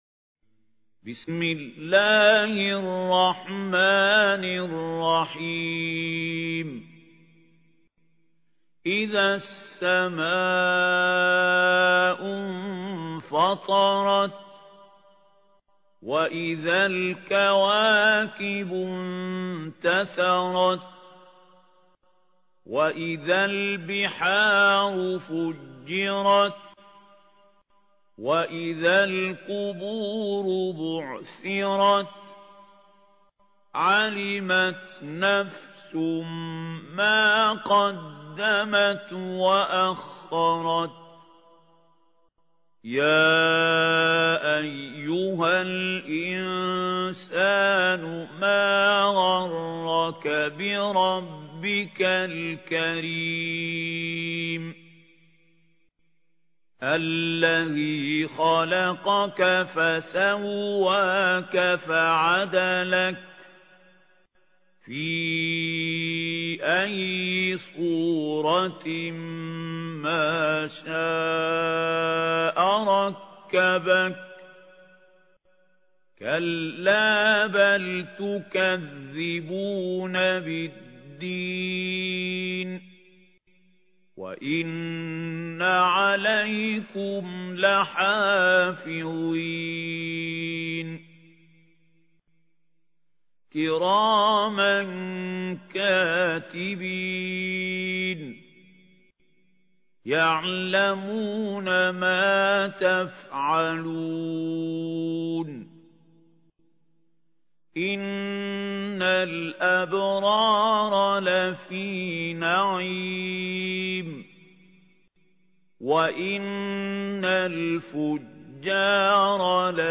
ترتيل
سورة الإنفطار الخطیب: المقريء محمود خليل الحصري المدة الزمنية: 00:00:00